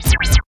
84 NOISE  -R.wav